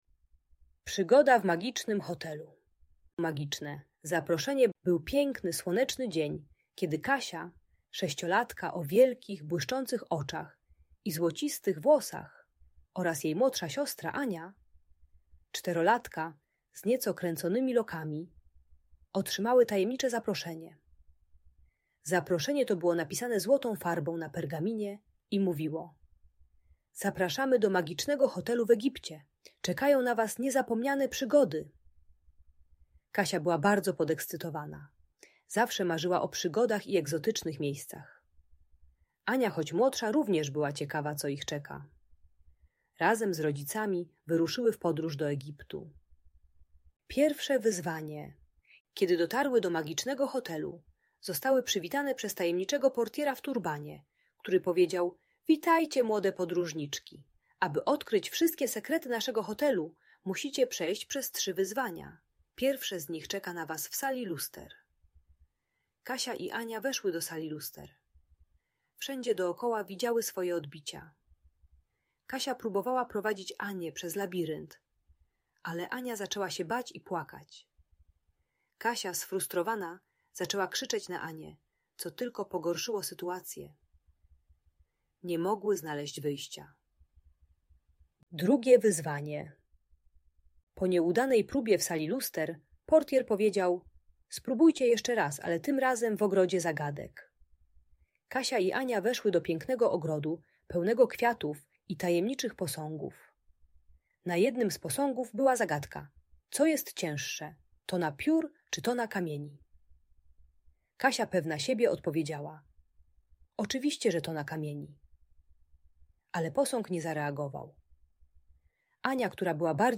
Przygoda w Magicznym Hotelu - Bunt i wybuchy złości | Audiobajka